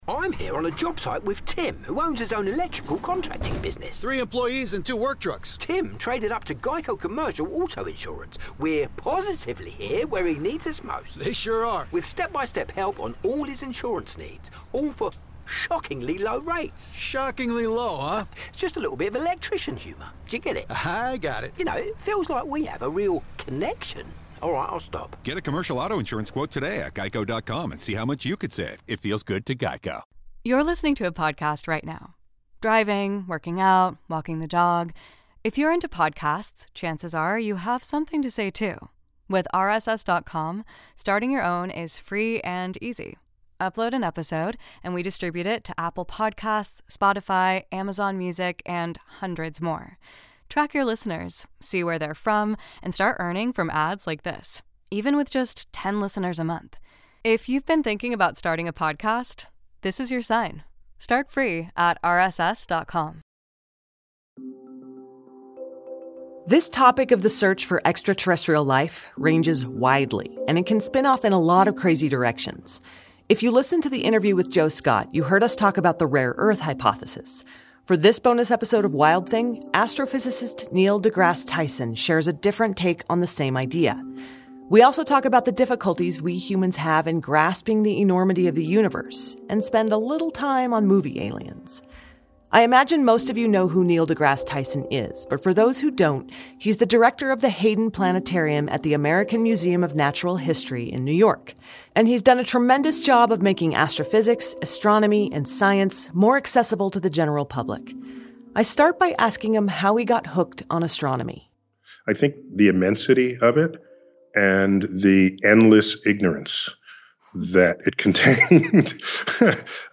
Wild Thing is re-releasing its bonus interviews! Astrophysicist Neil deGrasse Tyson gives his perspective on the rare earth hypothesis, the difficulty of wrapping one's head around the universe, and the problem with movie aliens.